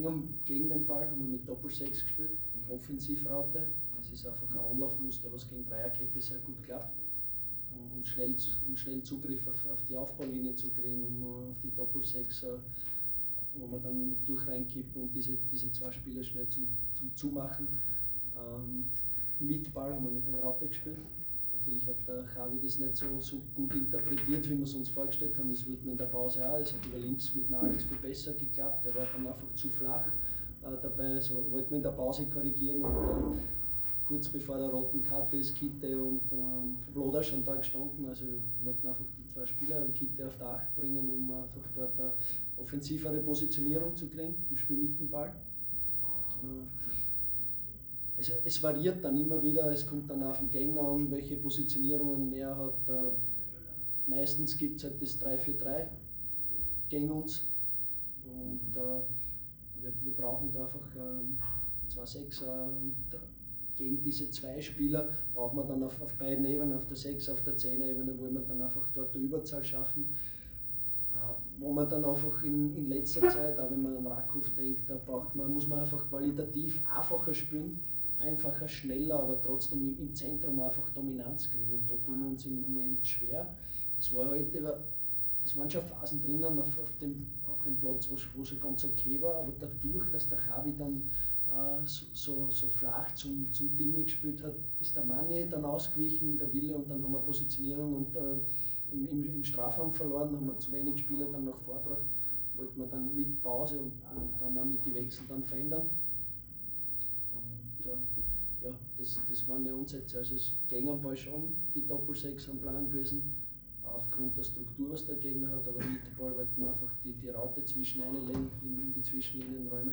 Cheftrainer Christian Ilzer bei der Pressekonferenz nach dem Unentschieden in Linz.